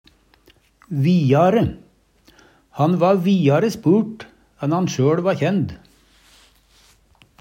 vi'are - Numedalsmål (en-US)